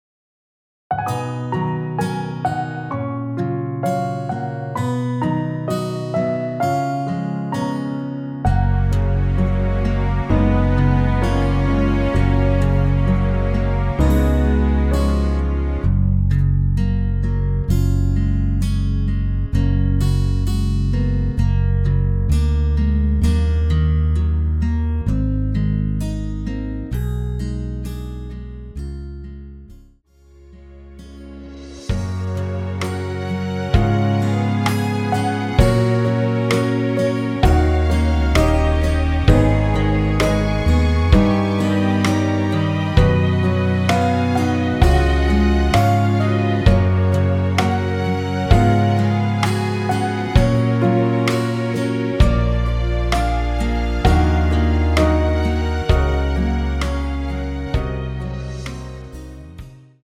(-1) 내린 MR 입니다.(미리듣기 참조)
F#
◈ 곡명 옆 (-1)은 반음 내림, (+1)은 반음 올림 입니다.
앞부분30초, 뒷부분30초씩 편집해서 올려 드리고 있습니다.